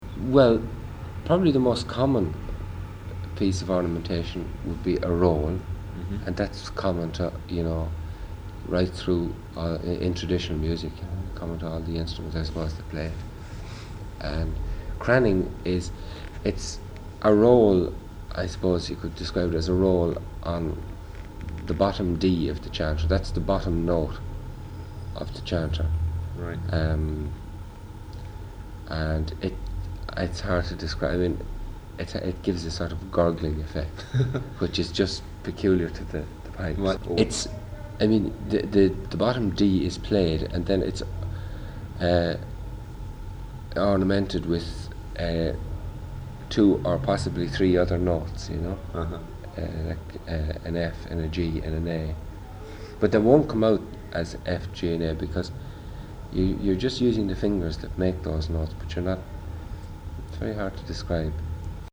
Here are some interviews I did when I was writing for GI (along with a few sound-bites)…